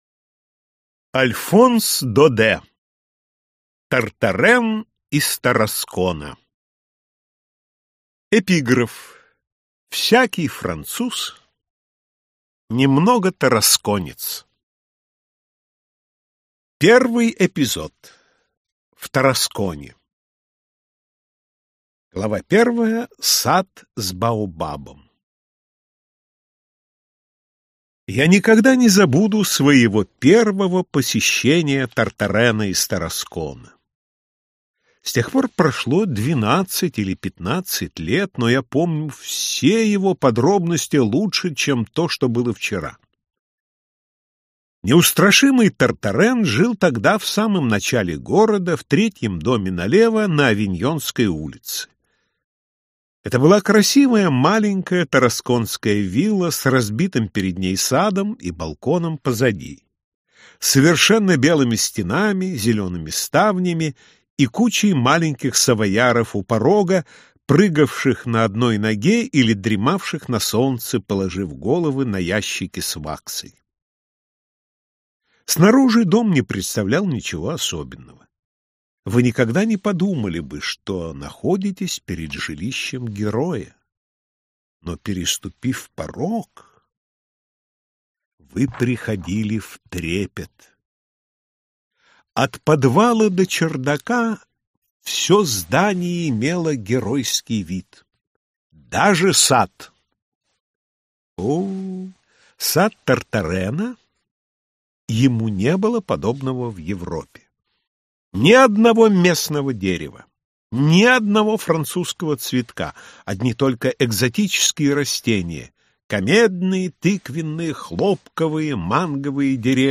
Аудиокнига Тартарен из Тараскона | Библиотека аудиокниг